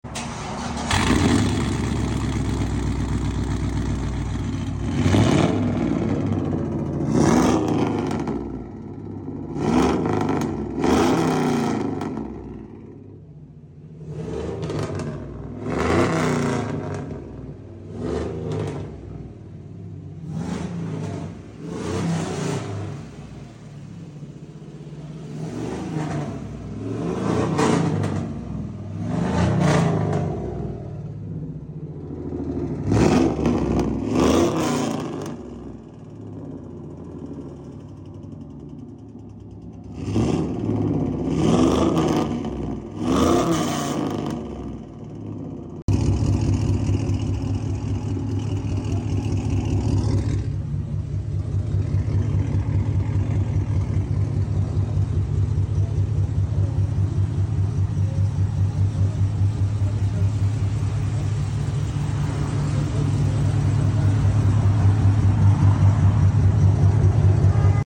Check out this Chevy Tahoe sound effects free download
Check out this Chevy Tahoe in for a whole new setup we went with a straight pipe along with tail pipe and a 4inch dual slanted tip